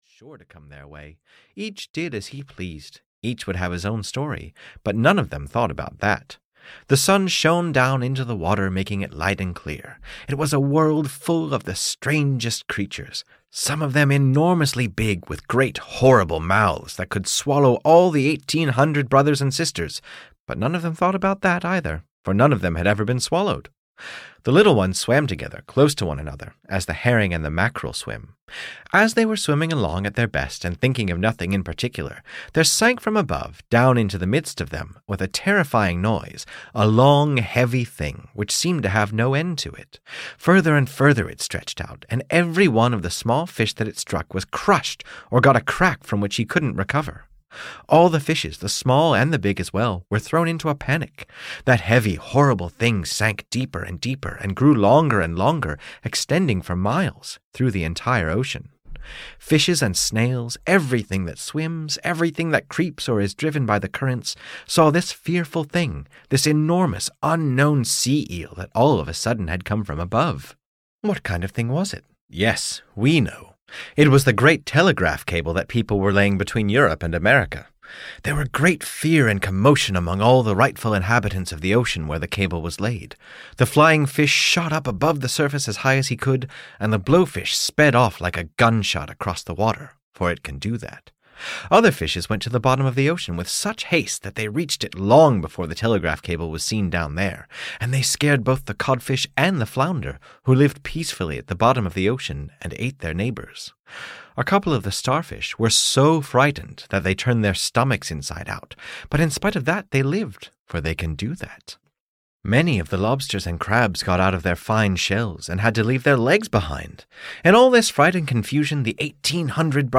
The Great Sea Serpent (EN) audiokniha
Ukázka z knihy